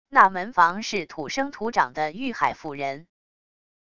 那门房是土生土长的玉海府人wav音频生成系统WAV Audio Player